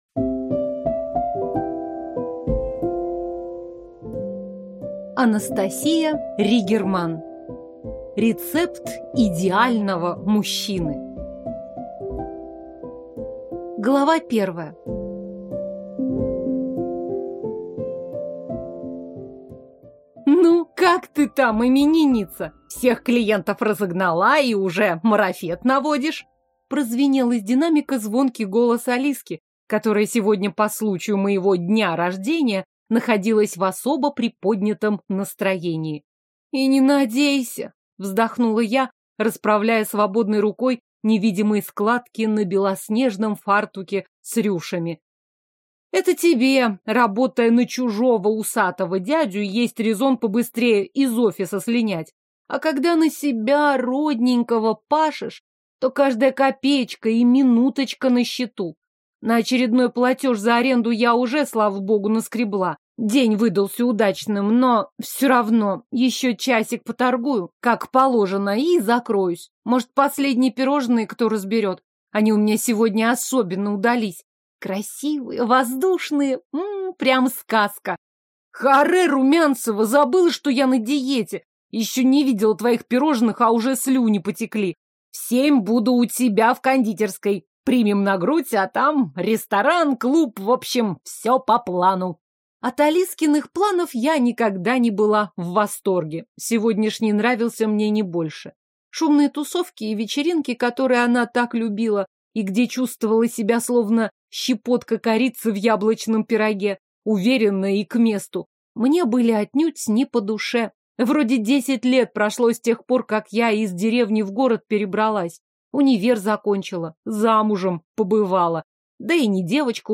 Аудиокнига Рецепт идеального мужчины | Библиотека аудиокниг